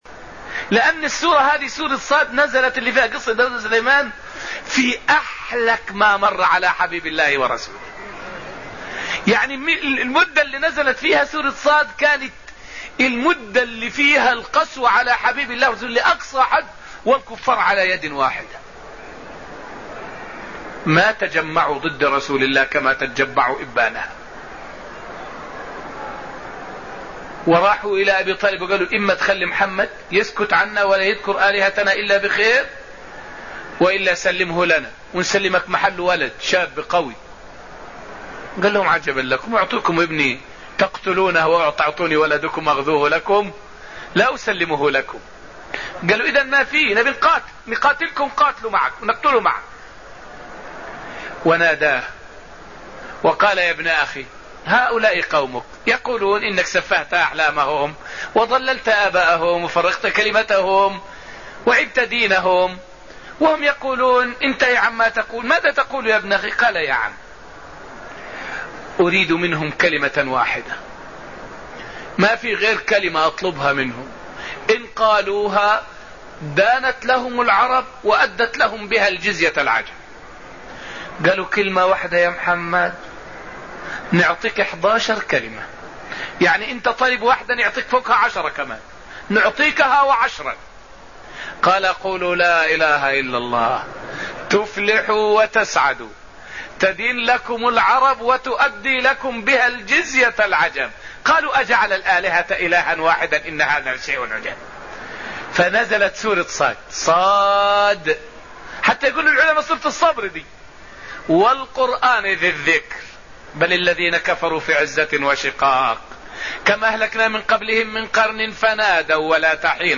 فائدة من الدرس السابع من دروس تفسير سورة النجم والتي ألقيت في المسجد النبوي الشريف حول متى نزلت سورة "ص" ولماذا سُمّيت سورة الصبر.